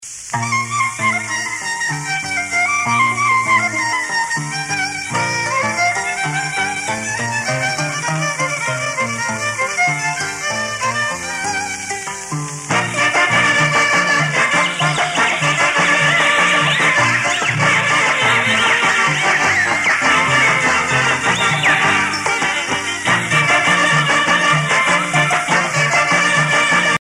danse : huayno (Pérou)
Pièce musicale éditée